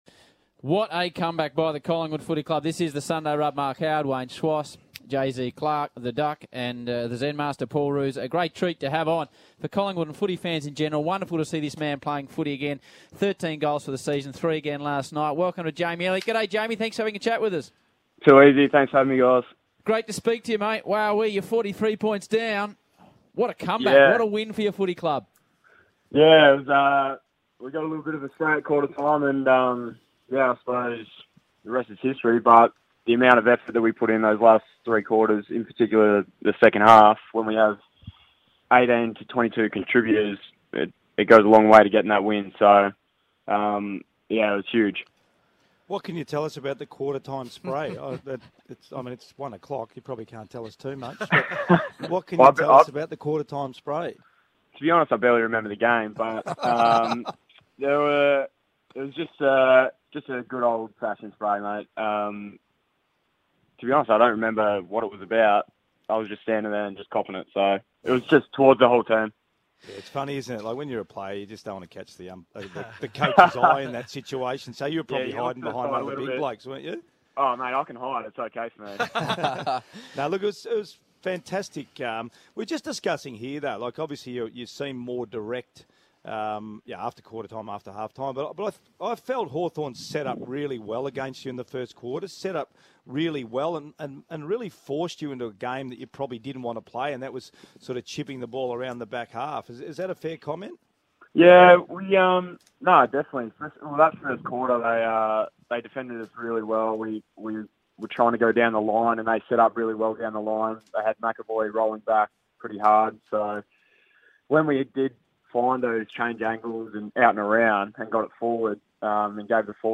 Listen to Collingwood forward Jamie Elliott chat to Triple M after the Magpies' thrilling victory over Hawthorn on Saturday night.